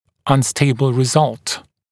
[ʌn’steɪbl rɪ’zʌlt][ан’стэйбл ри’залт]нестабильный результат